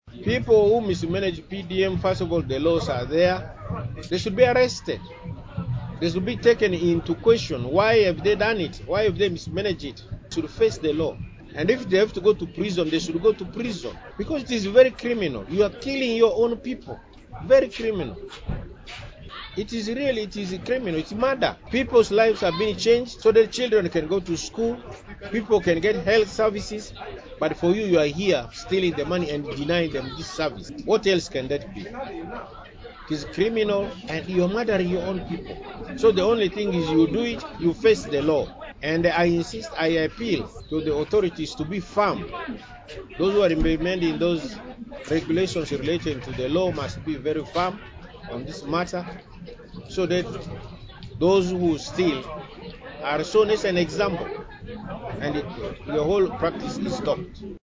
The State Minister for Land, Housing, and Urbanization, Hon. Mario Obiga Kania, who also serves as the Area Member of Parliament for Terego East, took a firm stand during his Independence Day address on October 9, 2023. Speaking to the people of Terego at Perea Primary School in Oriama Sub County, Terego District, he highlighted a pressing issue.